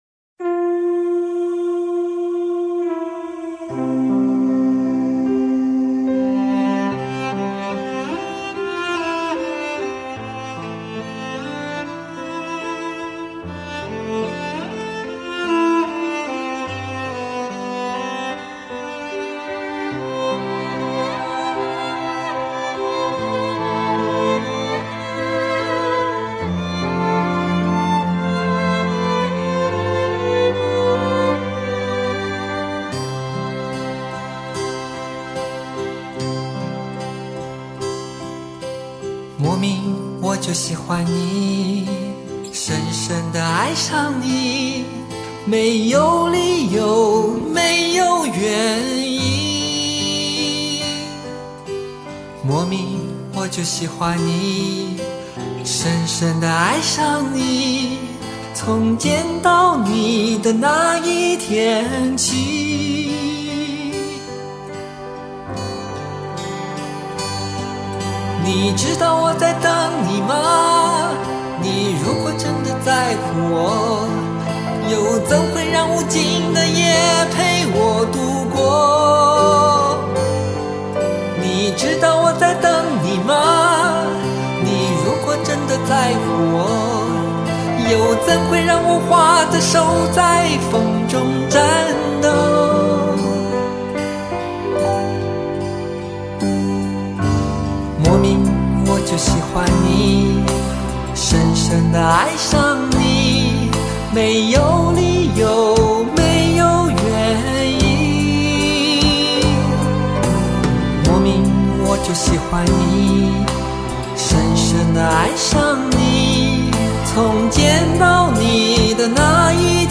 无损音质原人原唱，经典！值得聆听永久珍藏